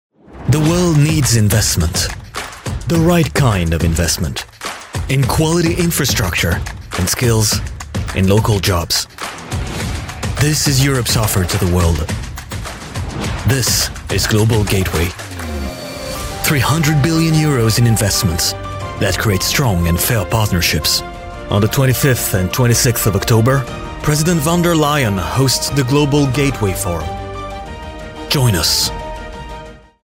Vídeos Corporativos
Eu geralmente trabalho na faixa de voz jovem/adulta, mas também posso fornecer vozes estranhas ou engraçadas atemporais!
Minha voz natural fica na faixa média-baixa e é perfeita para inspirar e criar confiança com um toque de calor e acessibilidade - ideal para empregos corporativos, e-learnings e outros projetos "sérios" que exigem uma entrega confiável e inspiradora, mas calorosa e amigável.